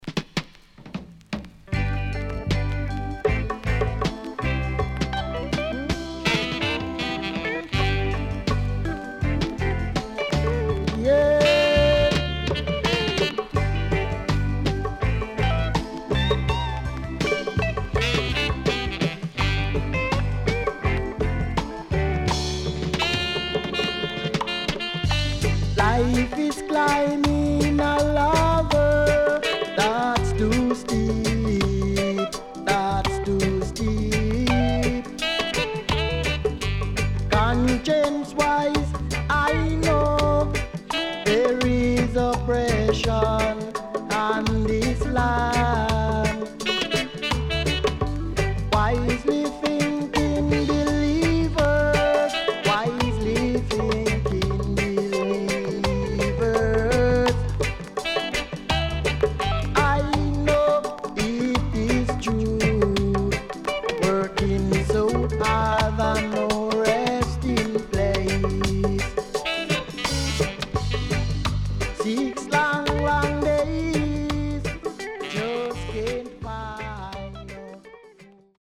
Fine Roots Vocal with Bongo